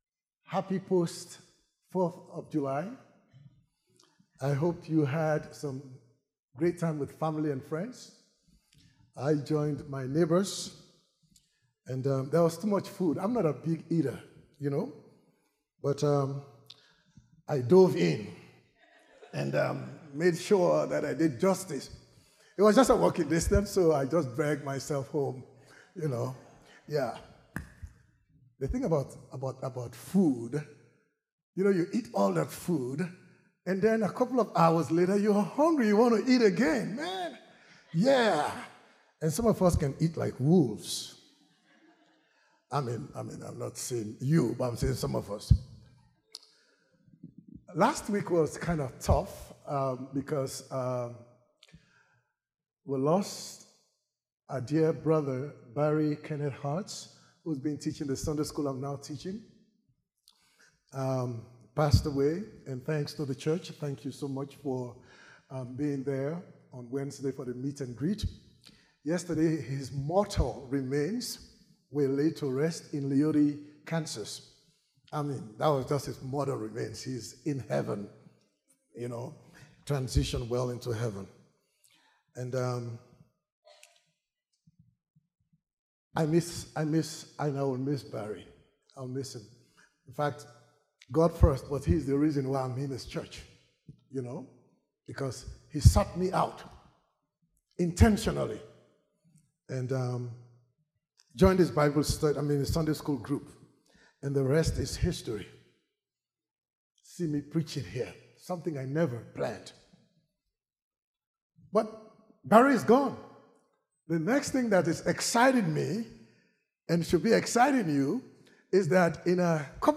" Transition Sermons " Sermons by guest speakers and preachers through the transition.